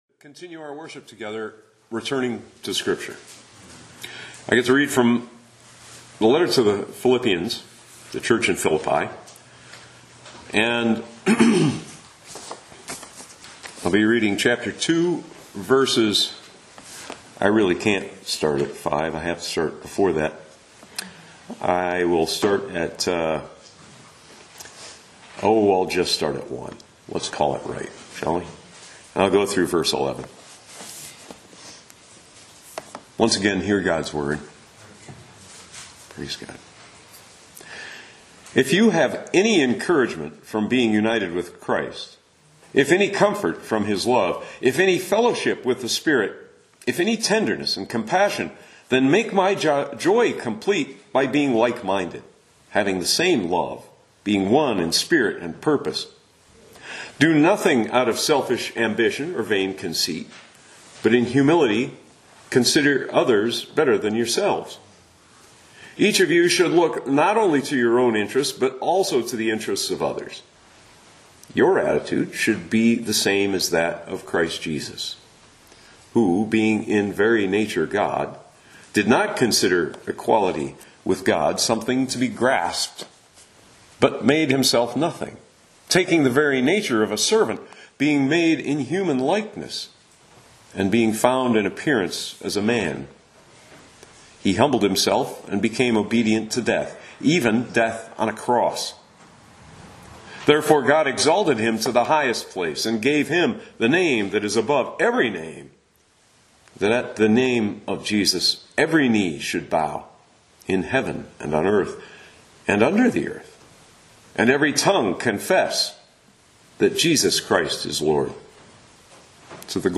Audio Sermons - Holland Bible Church